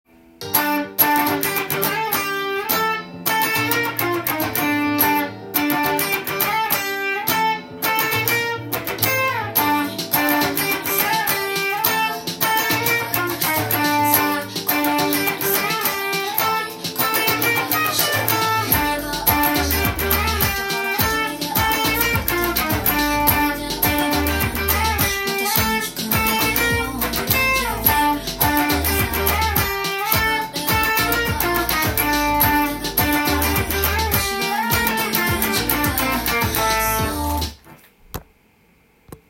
カッティングギターTAB譜
音源に合わせて譜面通り弾いてみました
はコード進行が切ない雰囲気になっています。
そのコード進行を意識しながらのカッティング奏法をTAB譜にしてみました。